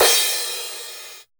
626 CRASH.wav